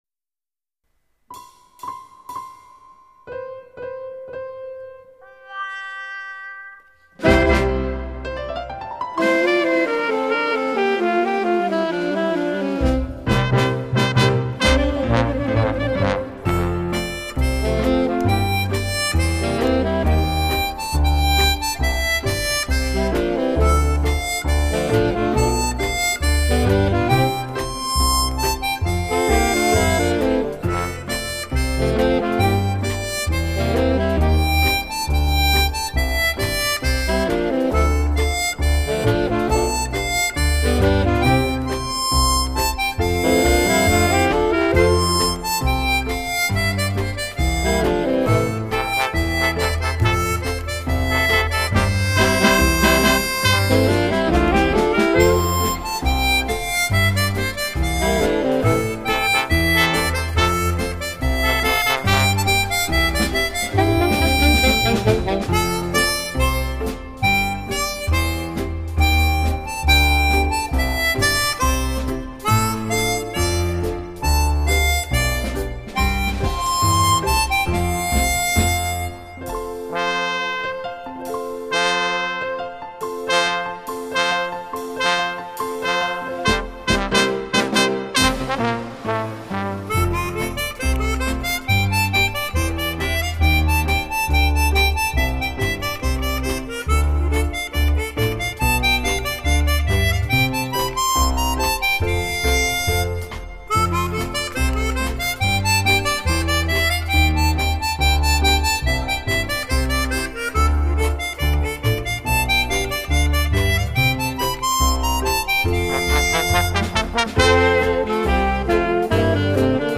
整张唱片口琴声音色细腻通透，犹如石库门弄堂深处传来的声音诉说着往事婉婉动听。